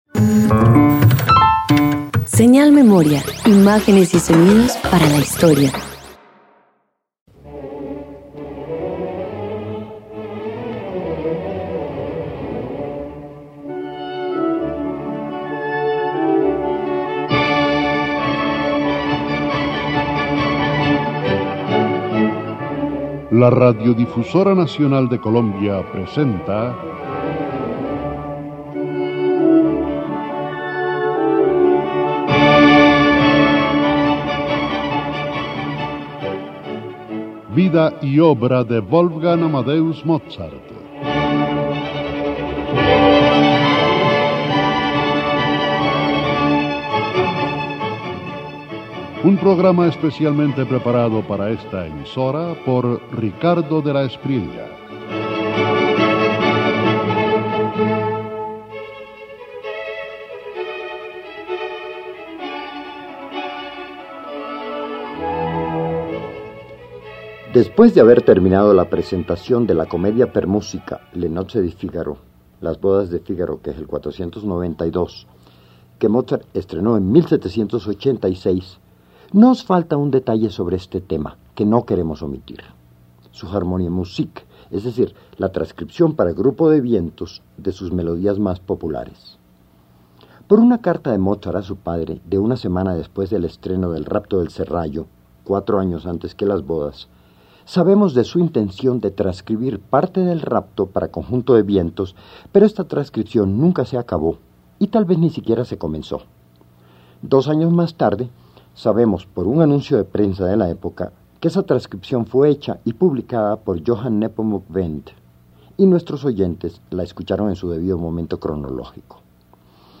249 Harmonie musik Apartes para conjuntos de vientos_1.mp3